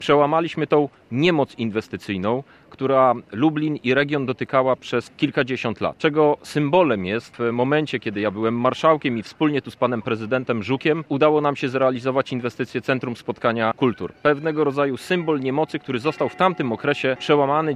Kandydaci Koalicji Europejskiej do europarlamentu z województwa lubelskiego zorganizowali konferencje prasową w 15. rocznicę wejścia Polski do struktur unijnych.